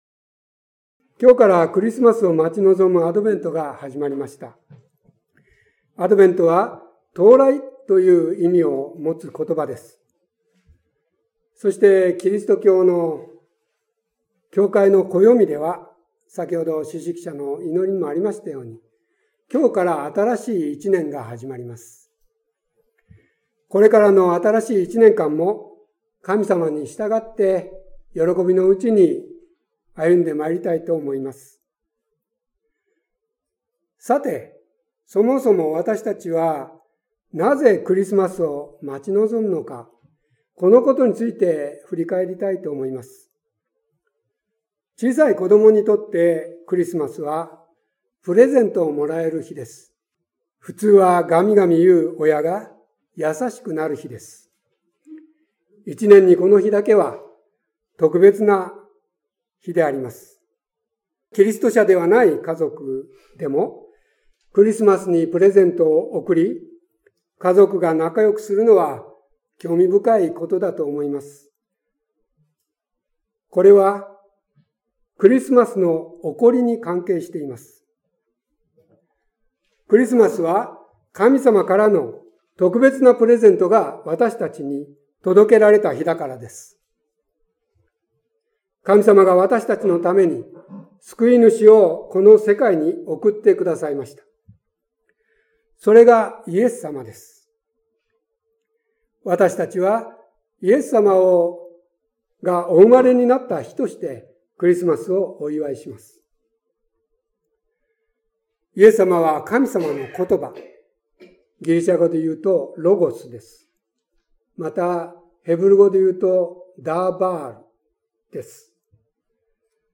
11月27日待降節第１主日礼拝説教「救いの約束」